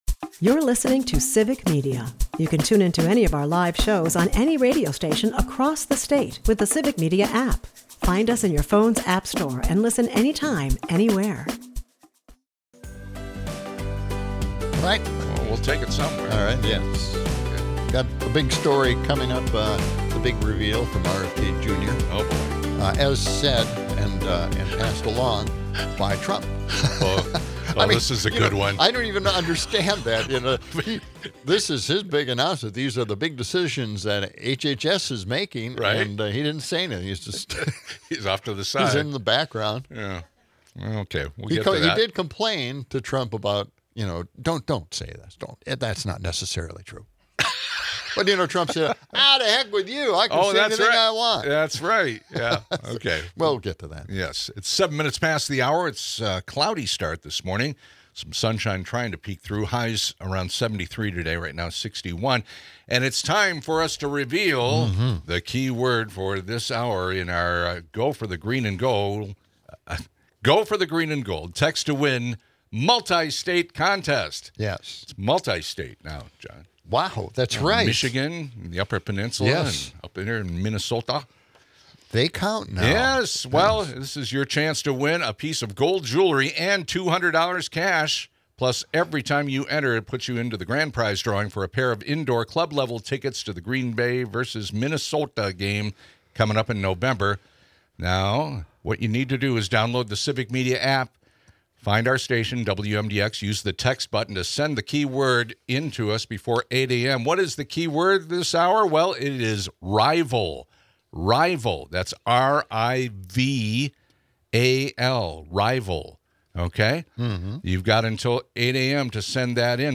Amidst the chatter, weather updates and local contests keep the vibe lively, as the hosts juggle humor and hard-hitting commentary.